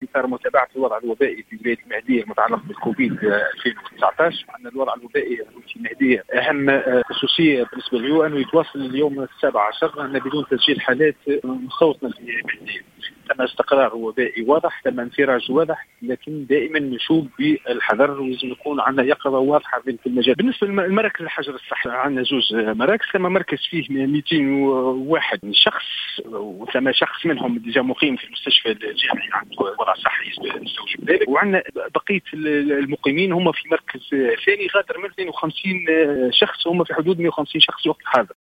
وأشار المتحدث في تصريح للجوهرة أف-أم، الى أنه لم يتمّ تسجيل حالات إصابات مستوطنة جديدة بفيروس كورونا المستجد لليوم 17 على التوالي.